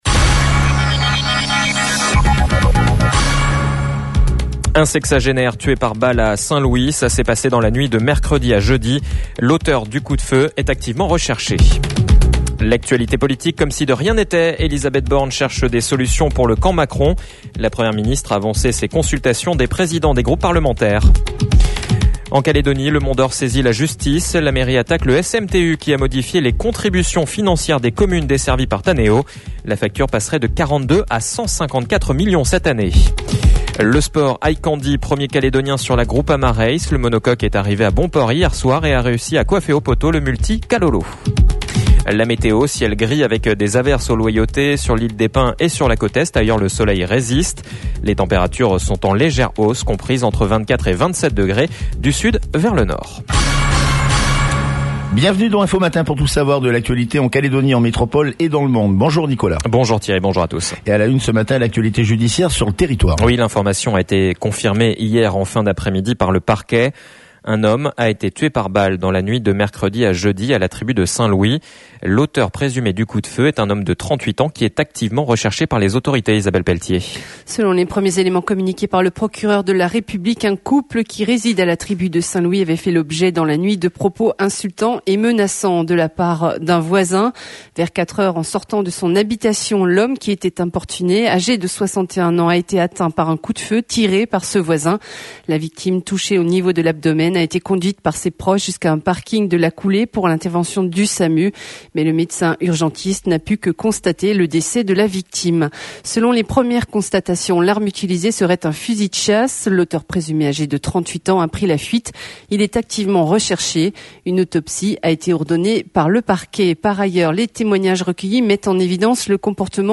JOURNAL : VENDREDI 24/06/22 (MATIN)